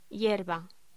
Locución: Hierba